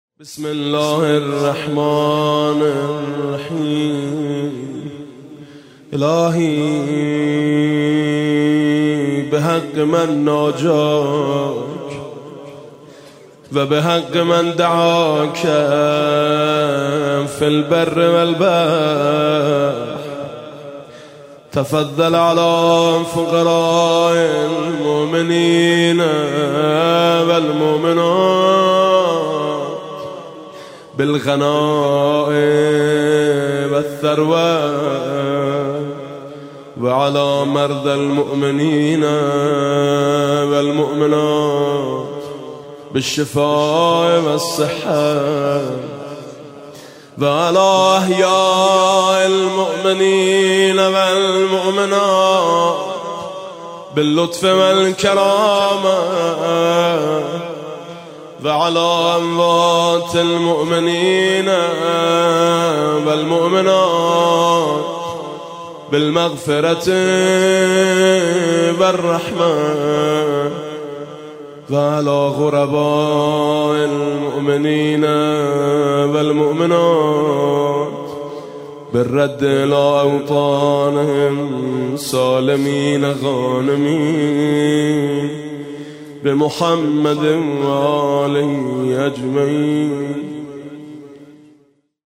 مسجد شهید بهشتی - 27 اردیبهشت-دعای حضرت حجت (عج)